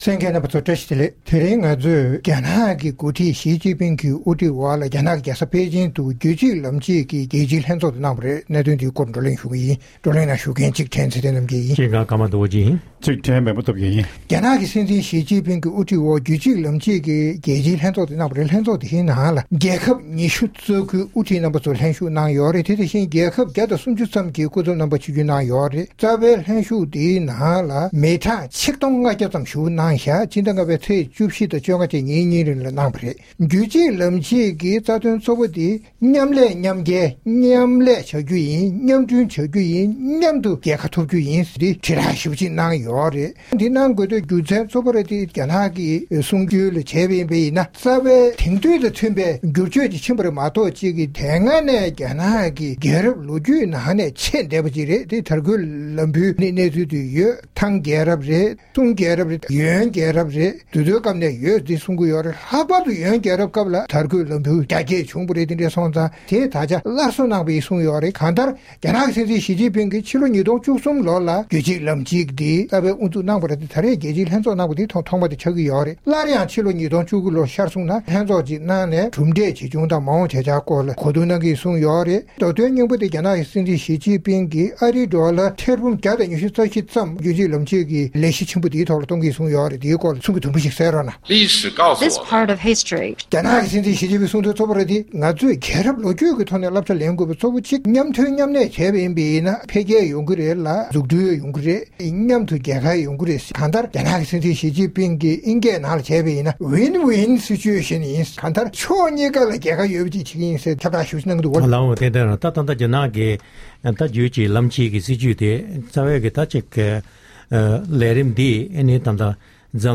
༄༅༎རྩོམ་སྒྲིག་པའི་གླེང་སྟེགས་ཞེས་པའི་ལེ་ཚན་ནང་།རྒྱ་ནག་གི་རྒྱལ་ས་པེ་ཅིང་དུ་ཕྱི་ཟླ་༥་པའི་ཚེས་༡༤་དང་༡༥་བཅས་ཉིན་གཉིས་རིང་རྒྱུད་གཅིག་ལམ་གཅིག་ཅེས་པའི་རྒྱལ་སྤྱིའི་ལྷན་ཚོགས་གནང་ཡོད་པའི་སྐོར་རྩོམ་སྒྲིག་འགན་འཛིན་རྣམ་པས་བགྲོ་གླེང་གནང་བ་ཞིག་གསན་རོགས་གནང་།།